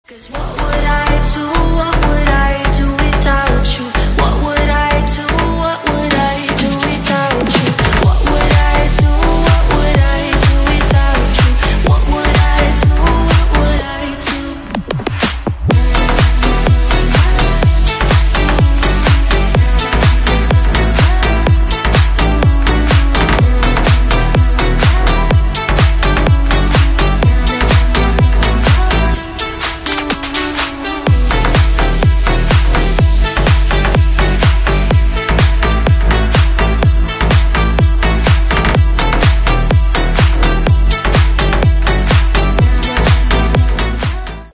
громкие
deep house
slap house